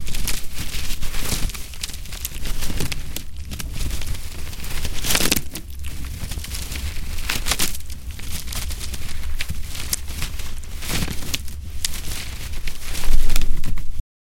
Имитация движения осьминога по корпусу корабля со звуковыми эффектами